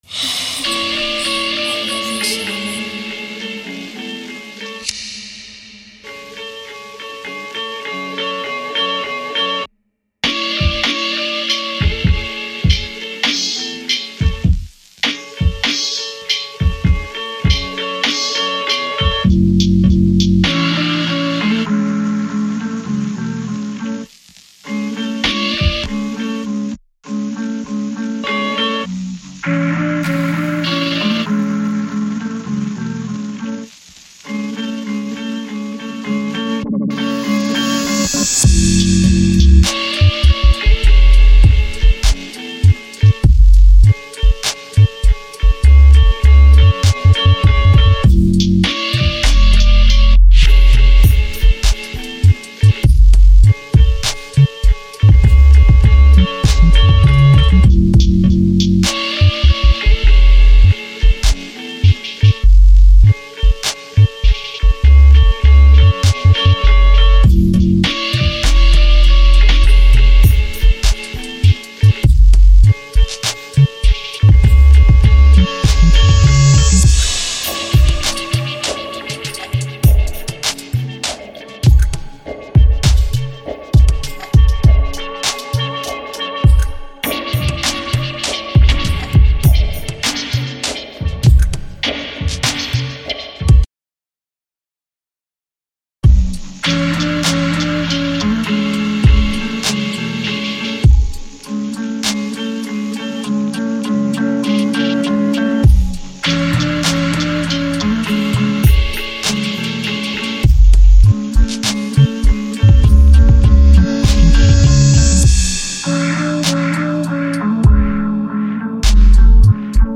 vibrant